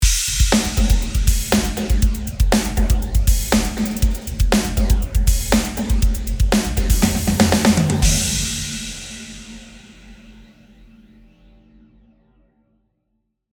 “Replika XT” はディレイプラグインですが、設定によってはリバーブのように使うこともできます。
左側、ディレイのスタイルを、物理的に存在しない反響音を作る “Diffusion” にし、右側、モジュレーションを “Phaser” にするとこんな感じになります。
replikaxt_phaser.mp3